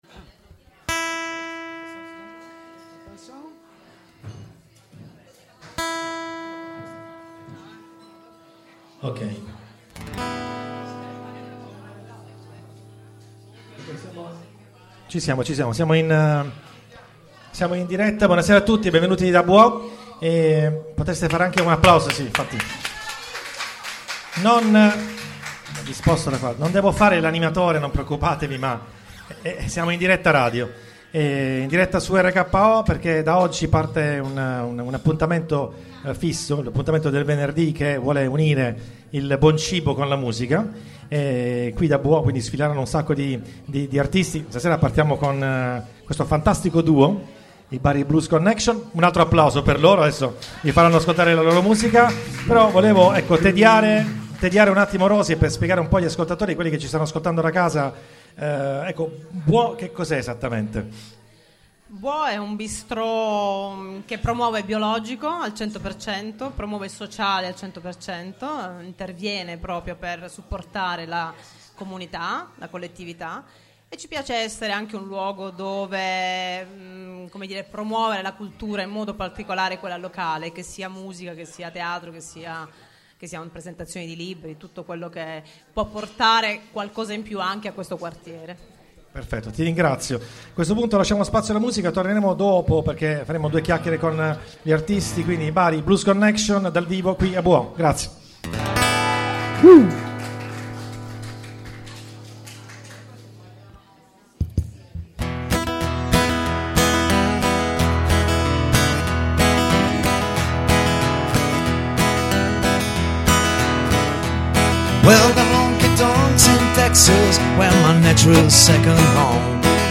Jazz&Blues
Ogni evento sarà supportato da RKO che trasmetterà i live alternati con le interviste della band!
aperibuo_live_bari_blues_connection.mp3